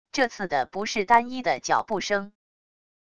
这次的不是单一的脚步声wav音频